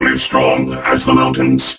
robo_bo_start_vo_01.ogg